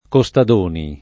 [ ko S tad 1 ni ]